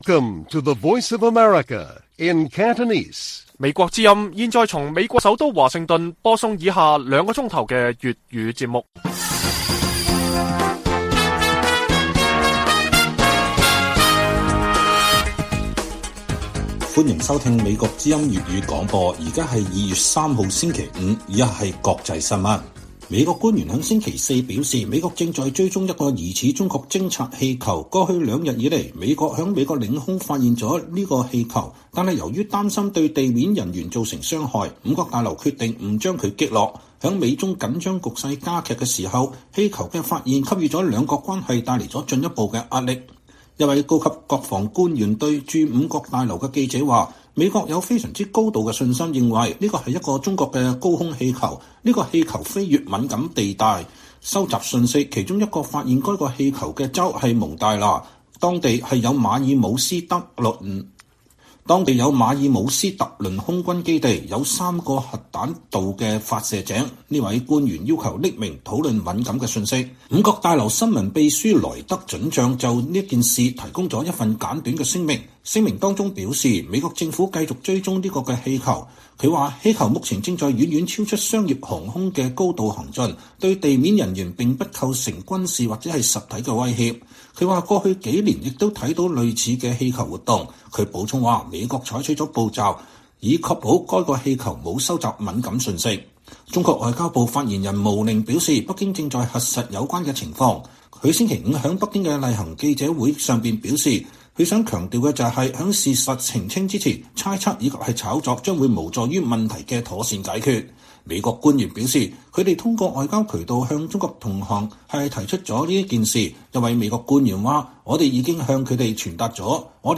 粵語新聞 晚上9-10點: 美國國防部發現中國間諜氣球飛越美國西部上空